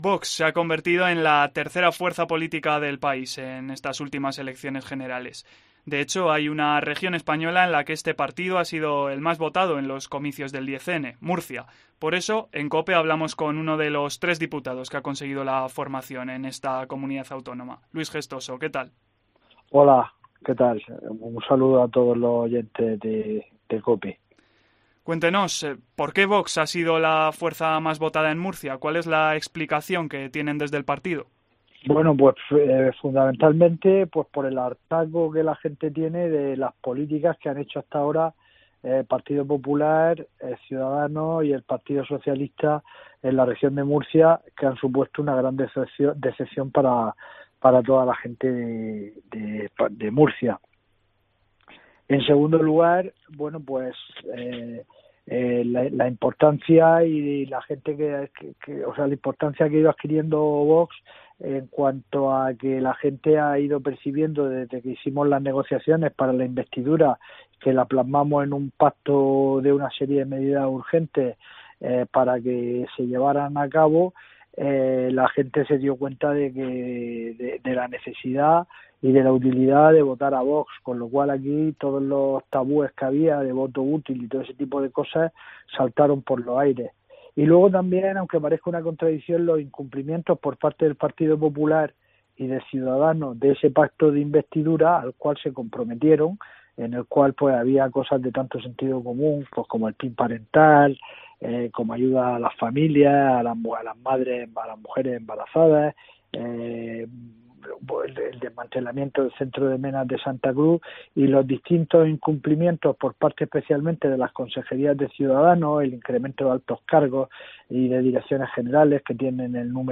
Luis Gestoso habla con COPE como futuro diputado de la única Comunidad Autónoma en la que su partido se ha impuesto en estas elecciones generales